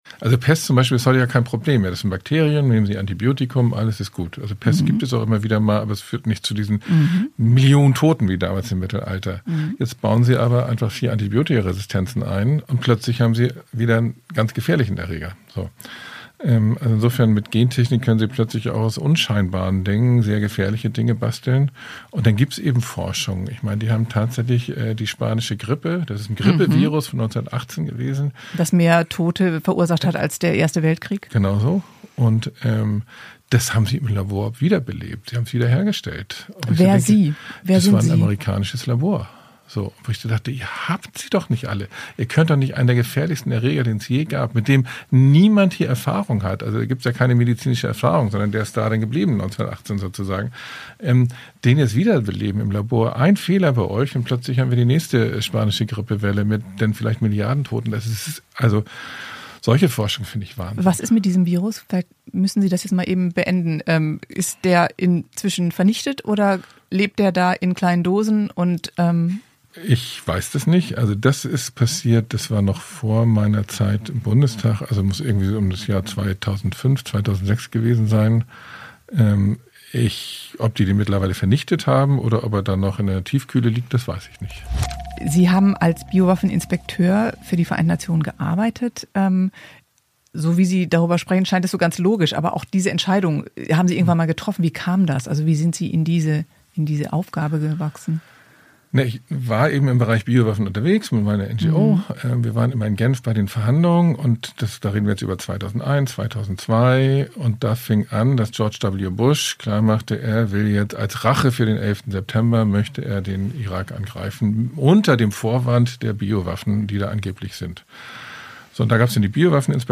Spitzenpolitikerinnen und -politiker im Gespräch